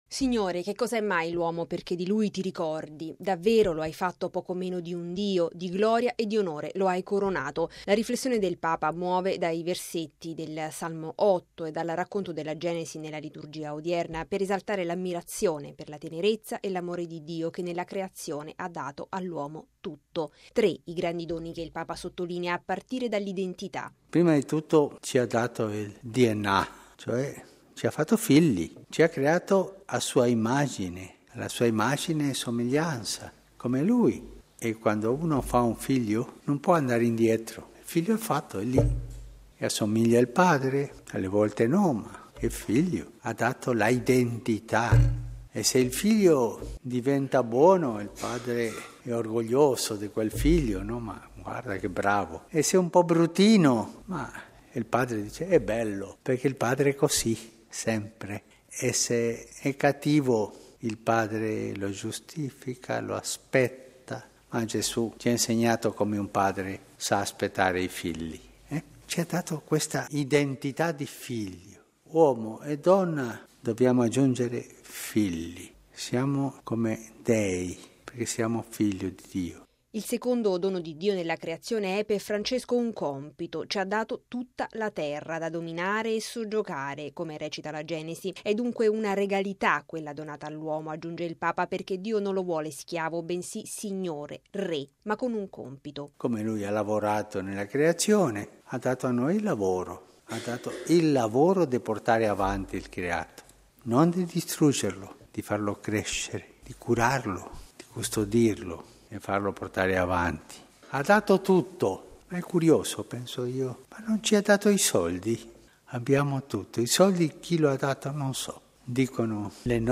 L’uomo fatto ad immagine di Dio, signore della terra e affiancato da una donna da amare. Sono questi i tre grandi doni di Dio all’uomo all’atto della Creazione, su cui il Papa incentra l’omelia della Messa mattutina a Casa Santa Marta, chiedendo la grazia di poterli custodire e portare avanti con l’impegno di tutti i giorni. Il servizio